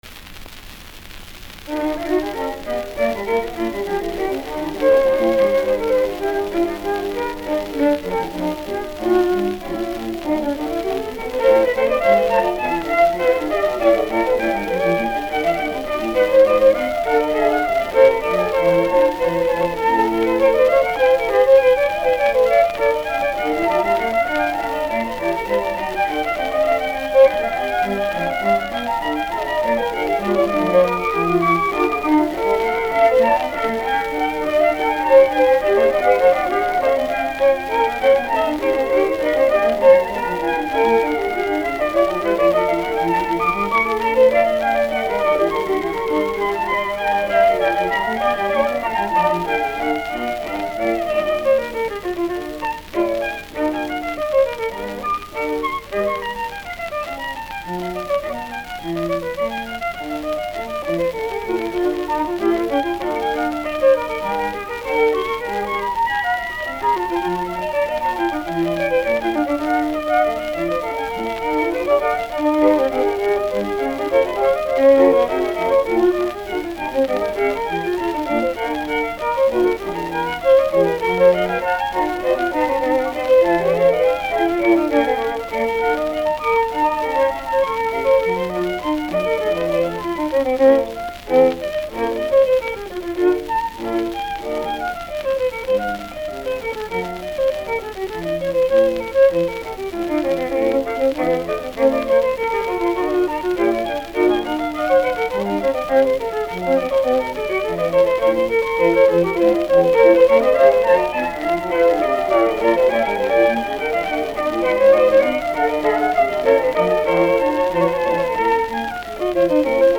F.-Kreisler-E.-Zimbalist-J.-S.-Bach-Concerto-For-Two-Violins-in-D-Minor-1-Vivace.mp3